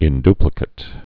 (ĭn-dplĭ-kĭt, -dy-)